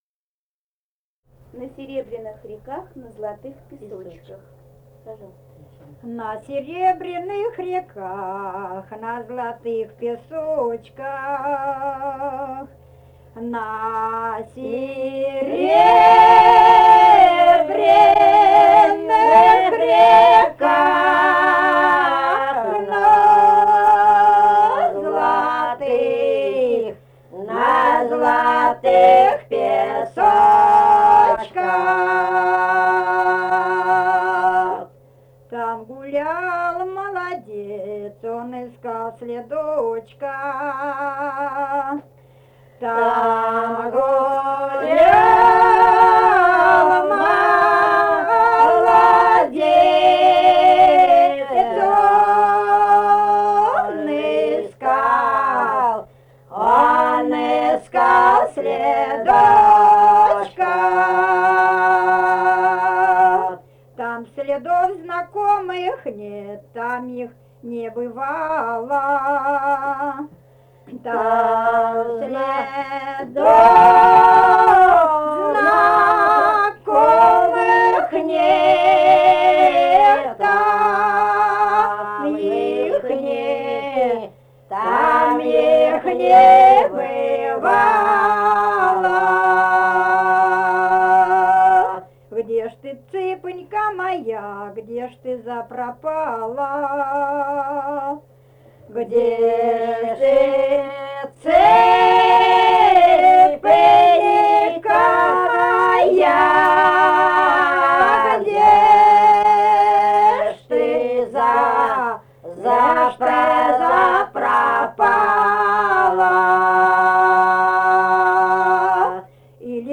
Этномузыкологические исследования и полевые материалы
«На серебряных реках» (лирическая).
Самарская область, с. Богатое Богатовского района, 1972 г. И1318-13